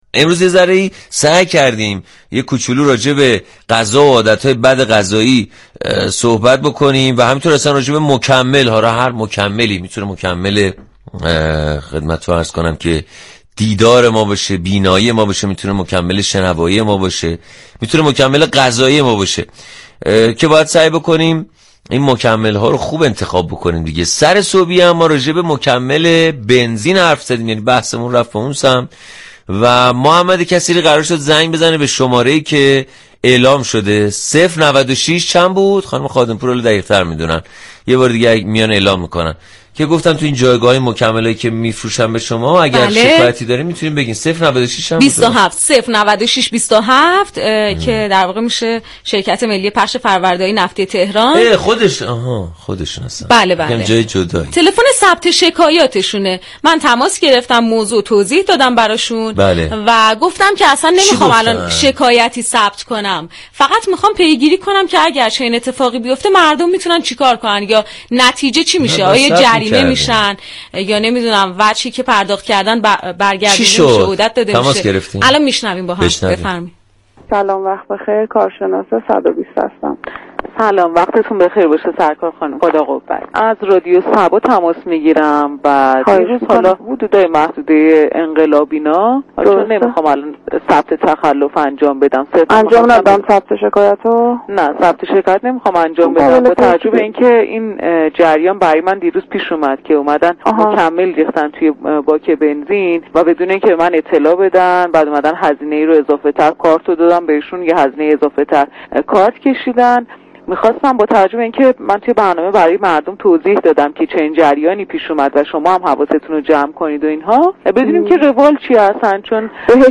این برنامه با رویكرد اطلاع رسانی در فضایی شاد و طنز از ساعت 6 صبح همراه مخاطبان می‌شود.
صبح صبا با شماره 09627 تماس گرفت و كارشناس شركت ملی فرآوردهای نفتی درباره اقدام هم وطنان در مشاهده با فروش اجباری مكمل های سوخت در جایگاه ها توضیح داد.